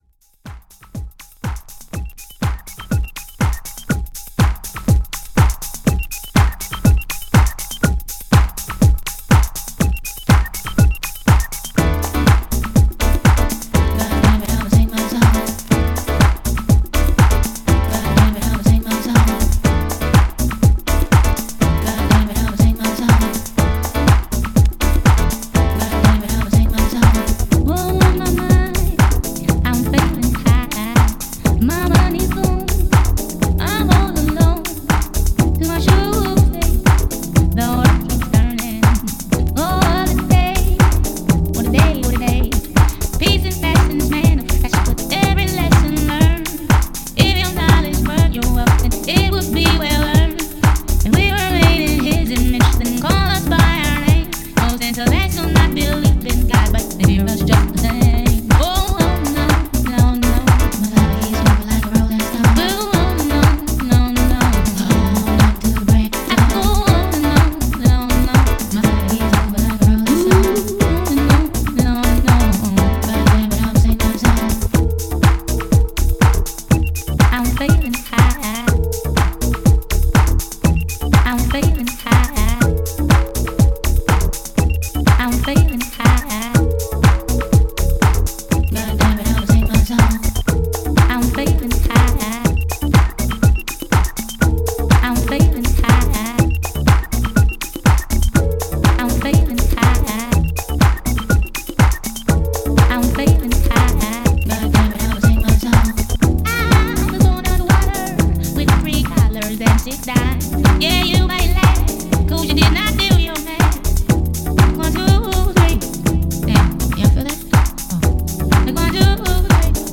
DANCE
試聴は"VOCAL HOUSE MIX"です。